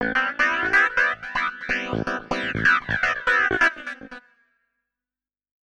SYNTH028_VOCAL_125_A_SC3(L).wav
1 channel